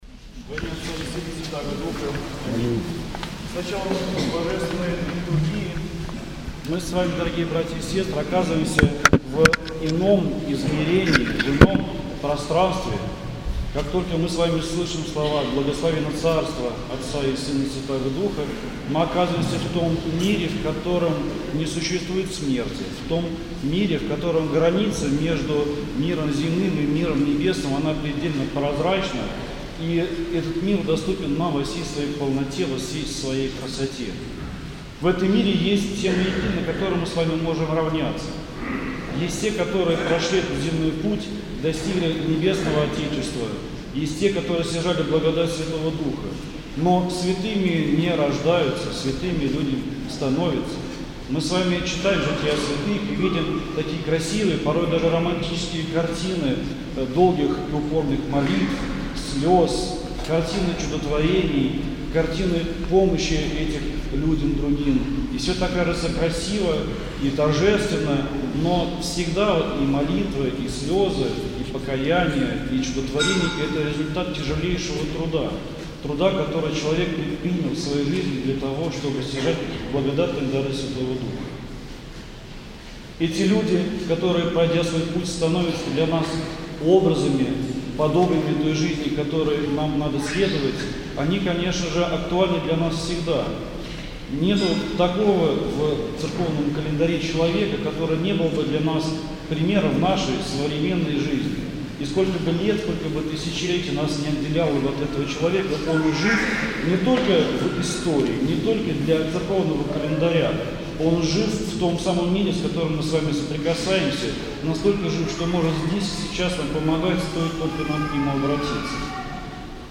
проповедь перед причастием